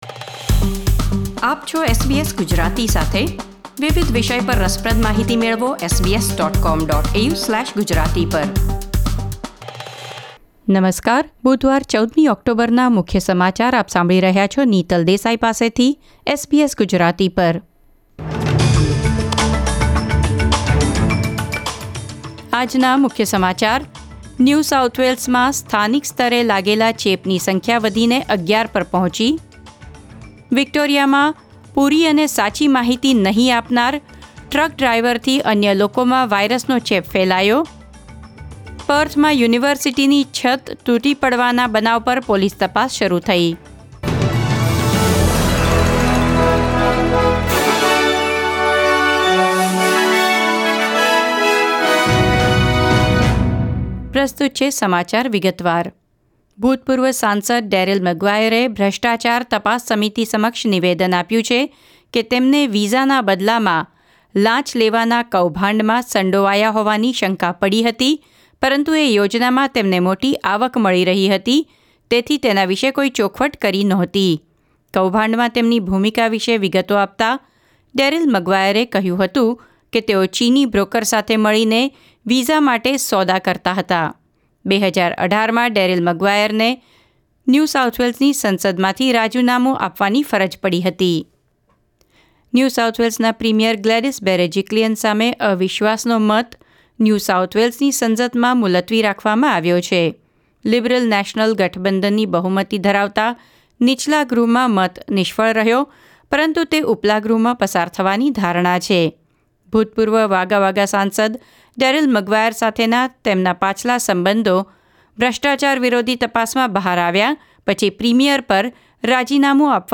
SBS Gujarati News Bulletin 14 October 2020